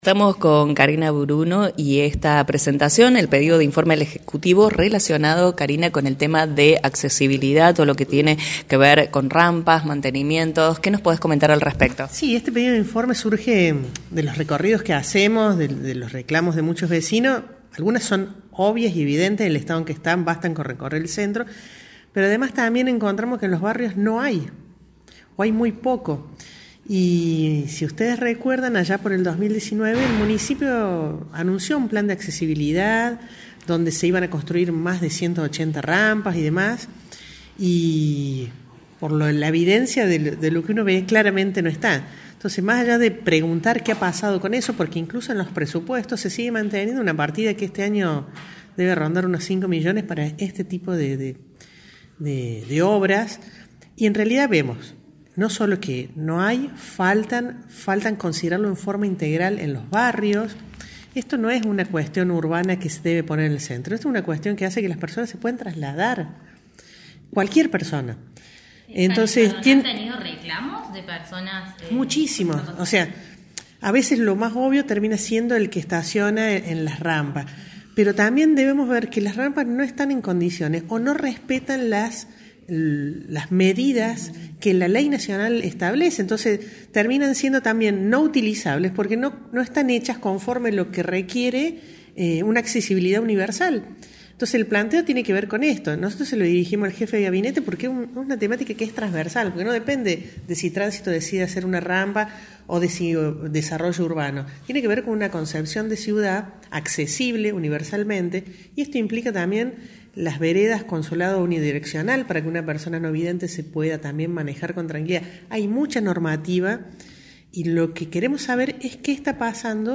AUDIO – KARINA BRUNO, CONCEJAL
En «La Mañana Informal» la Concejal dio detalles del pedido al Ejecutivo sobre este tema y otros que involucra la accesibilidad para personas con capacidades diferentes.
Concejal-Karina-Bruno.mp3